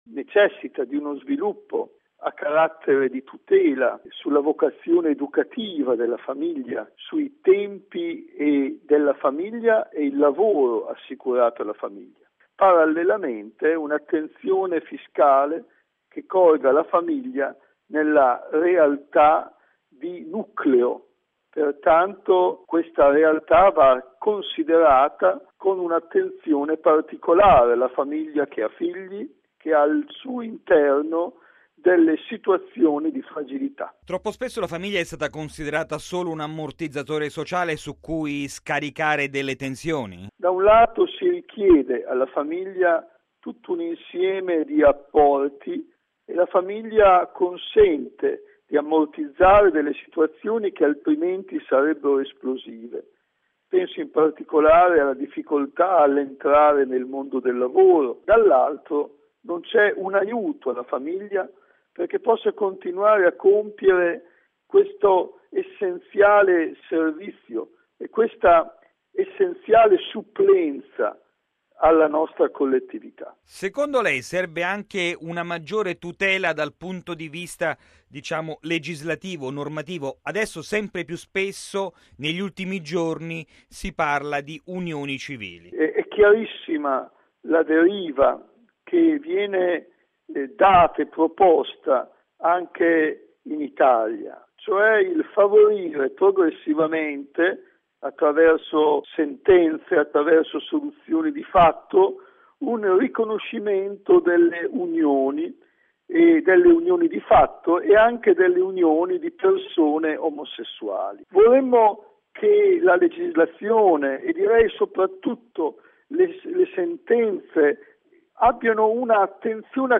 Per questo nel 2014 è necessario rafforzare le tutele fiscali e normative proprio a favore dei nuclei familiari. Di questo ne è convinto il vescovo di Parma Enrico Solmi, presidente della Commissione Episcopale per la Famiglia e la Vita, che interviene anche sulle unioni civili.